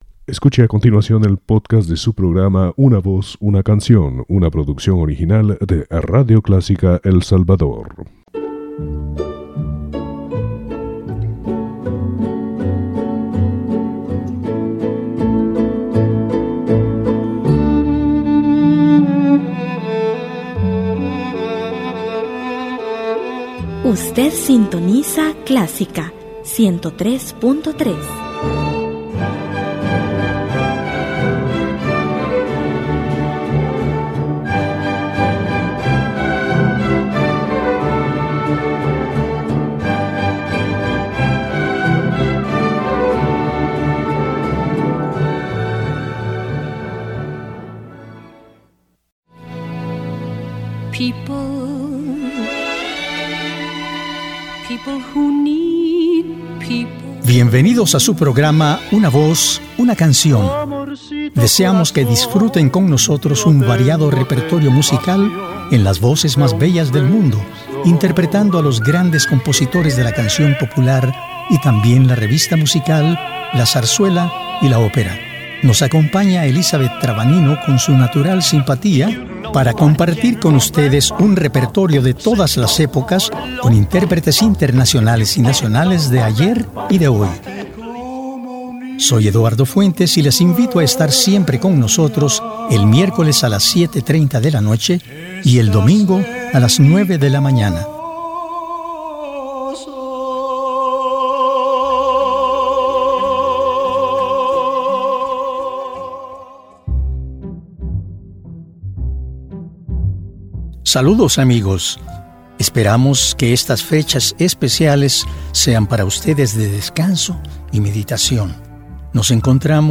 Toda la belleza del Bel Canto, la pasión de los grandes interpretes inmortales de la opera, el romanticismo inagotable de las canciones y los clásicos boleros.